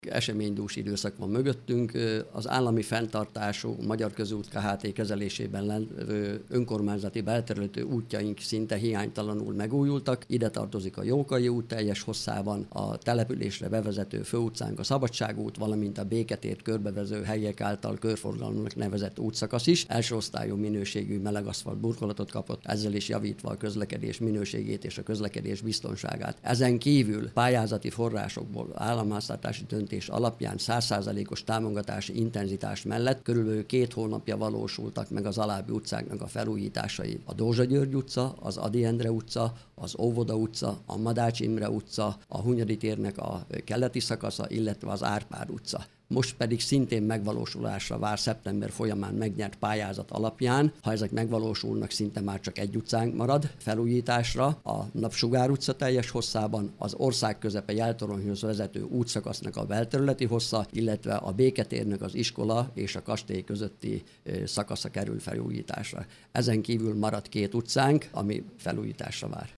Szinte minden utat sikerült felújítani az elmúlt időszakban Pusztavacson. A településen már csak három utca felújítása várat magára. Jóri László polgármester sorolta fel, mely utcákat érintett eddig a rekonstrukció.